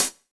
9HH FOOT.wav